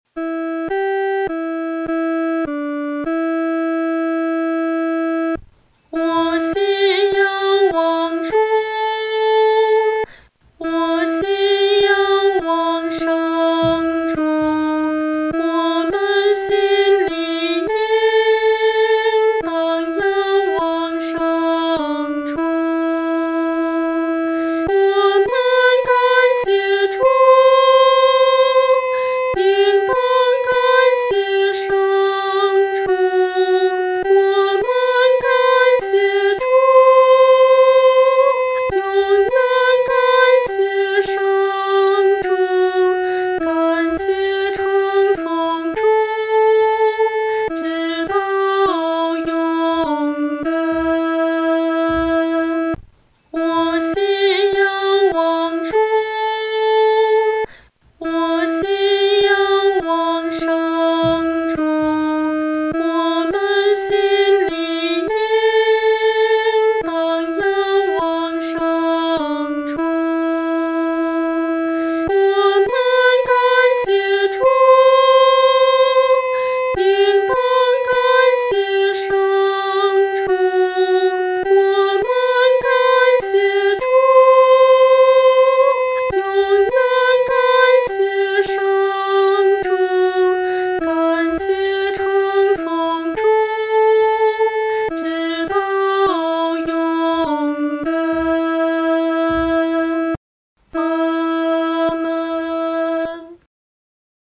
合唱
女高
本首圣诗由网上圣诗班 (石家庄二）录制